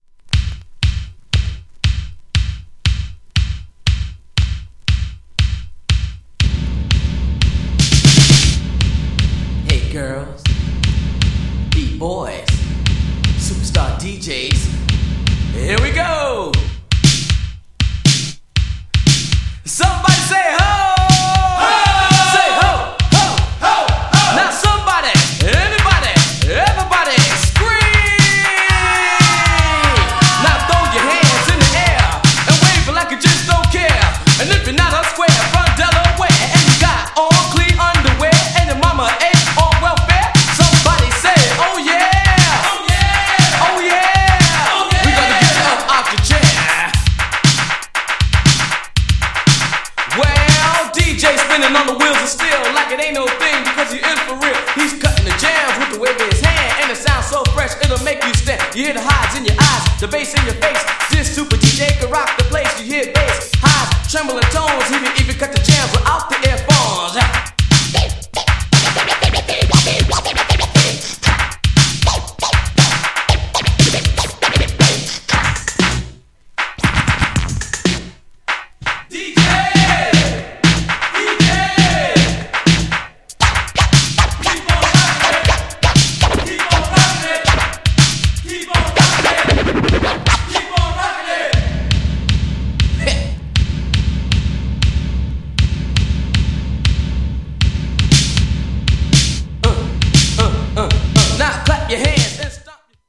GENRE Hip Hop
BPM 106〜110BPM